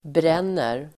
Uttal: [br'en:er]